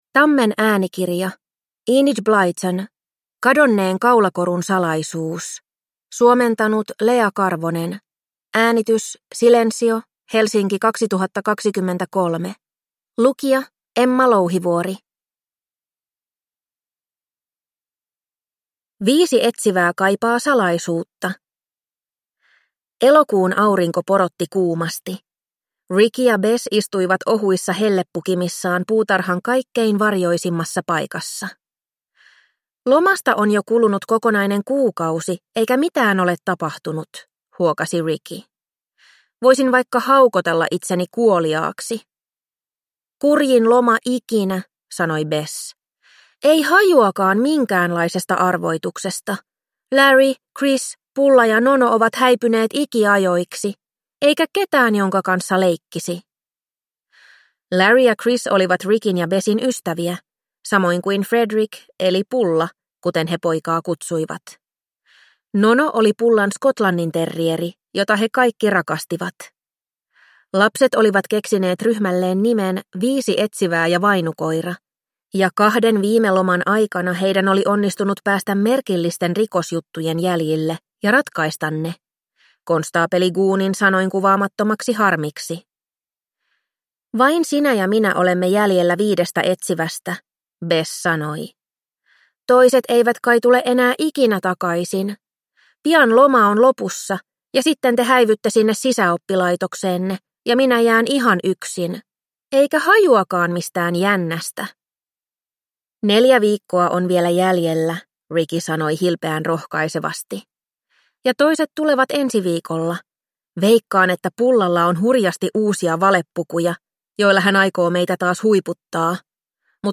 Kadonneen kaulakorun salaisuus – Ljudbok